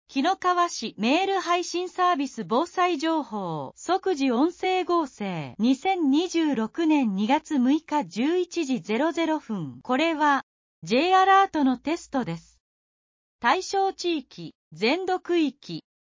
即時音声書換情報 投稿日時: 2026年2月6日 投稿者: kinokawacitynuser 紀の川市メール配信サービス【防災情報】 「即時音声合成」 2026年02月06日11時00分 これは、Jアラートのテストです。